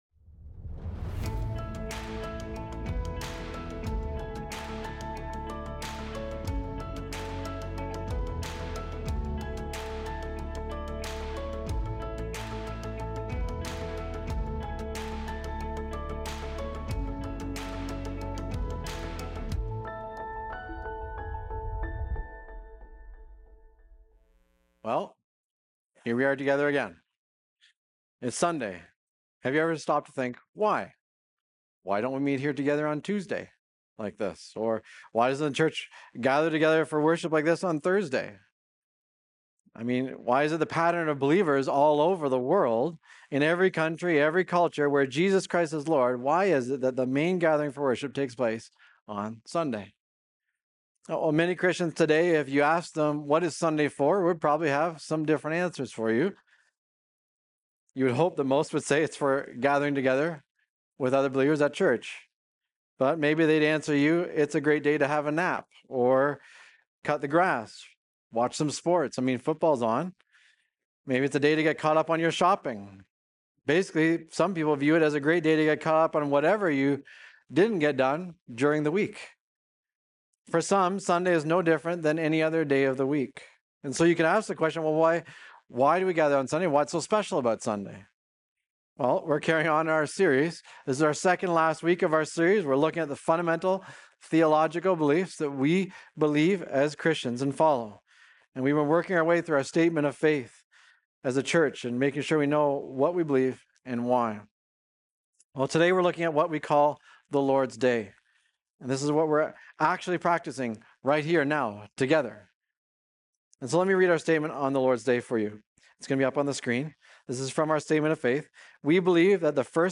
Recorded Sunday, November 16, 2025, at Trentside Fenelon Falls.